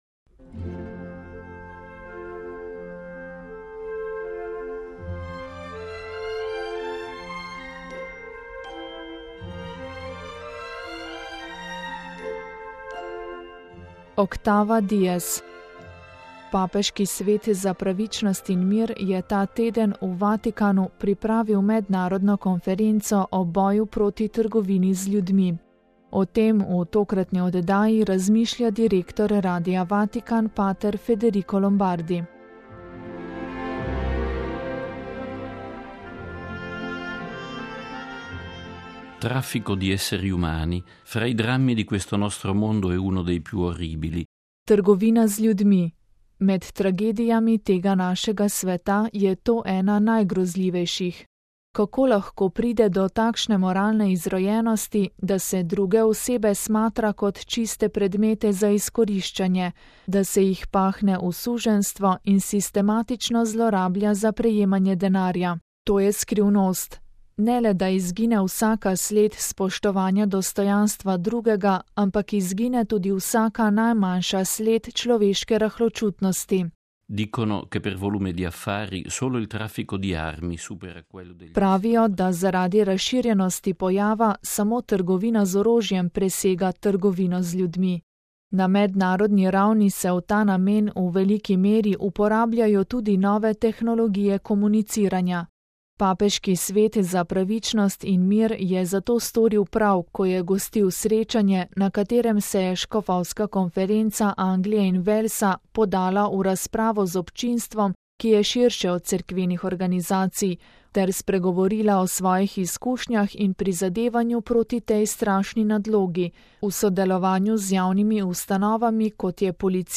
VATIKAN (sobota, 12. maj 2012, RV) – Papeški svet za pravičnost in mir je ta teden v Vatikanu pripravil mednarodno konferenco o boju proti trgovini z ljudmi. O tem v tokratni oddaji Octava dies razmišlja direktor Radia Vatikan, p. Federico Lombardi.